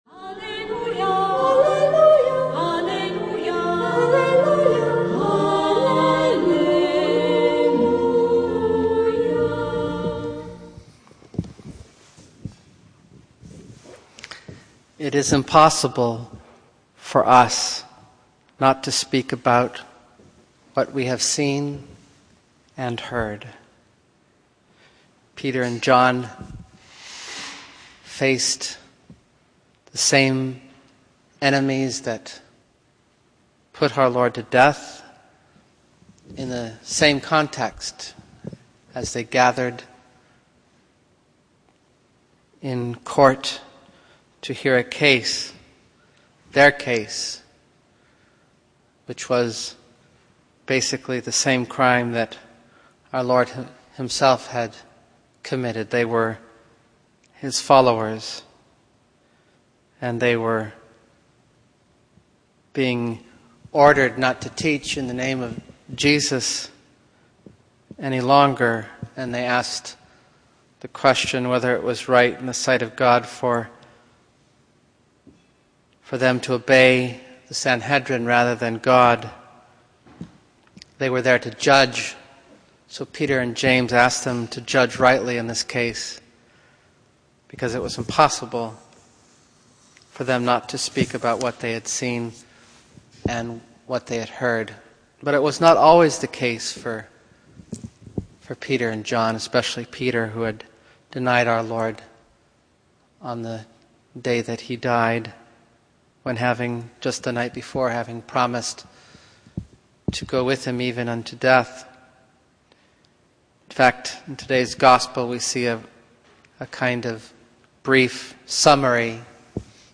Homily: Follow God or Follow “the State”?
Saturday Easter Homily